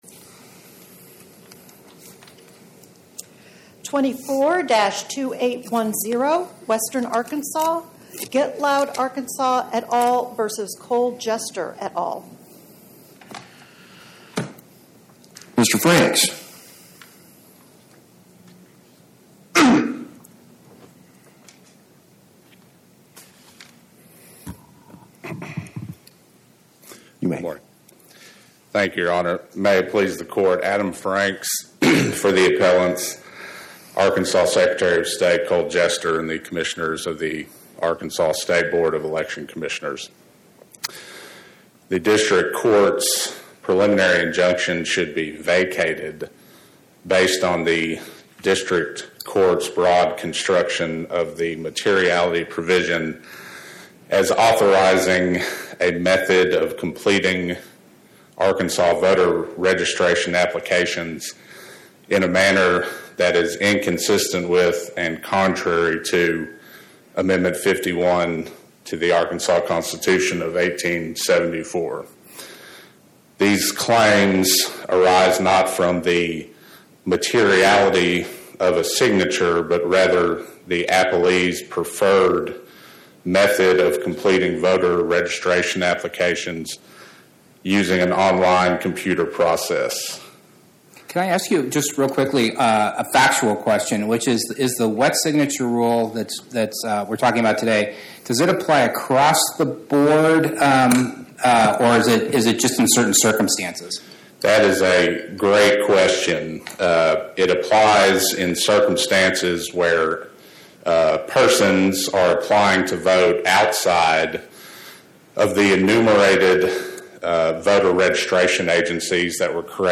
My Sentiment & Notes 24-2810: Get Loud Arkansas vs Cole Jester Podcast: Oral Arguments from the Eighth Circuit U.S. Court of Appeals Published On: Thu Sep 18 2025 Description: Oral argument argued before the Eighth Circuit U.S. Court of Appeals on or about 09/18/2025